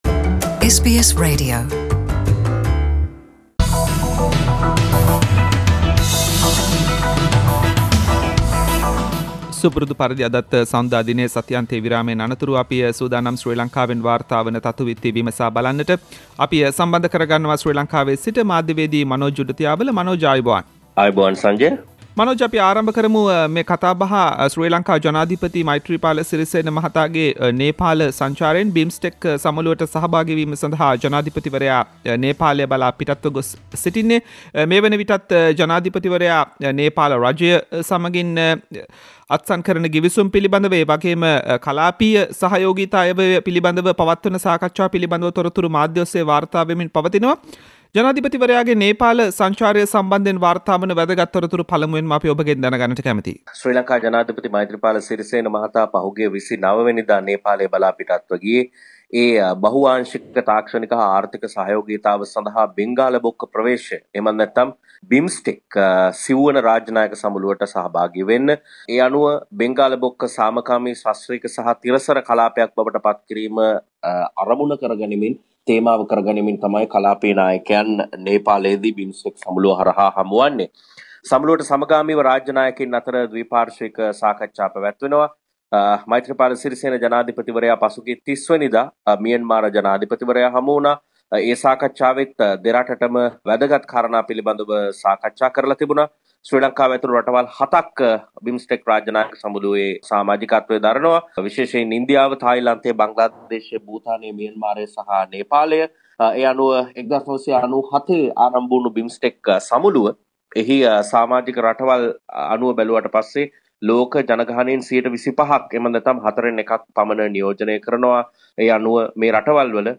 සැප්තැම්බර් 5දා ඒකාබද්ද විපක්ෂයෙන් දැවැන්ත ආණ්ඩු විරෝධී ජනරැලියක් - “SBS සිංහල” සතියේ දේශපාලනික විත්ති විමසුම